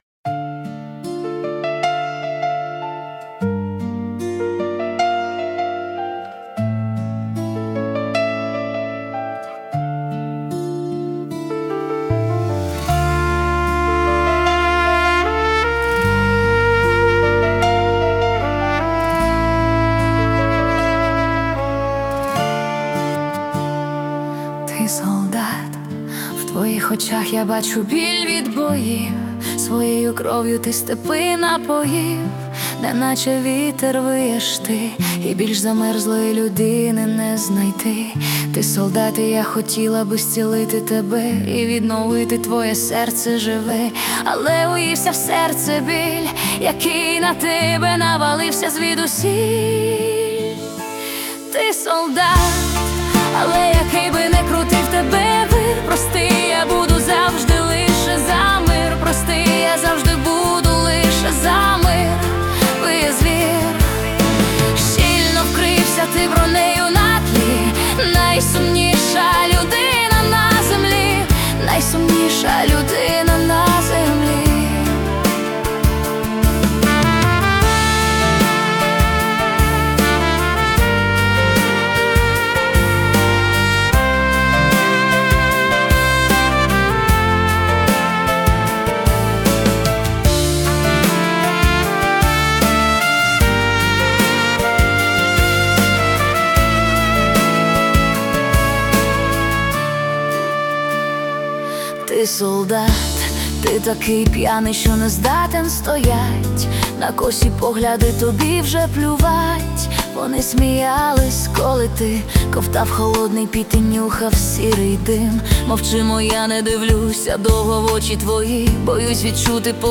це глибоко емоційна балада (87 BPM)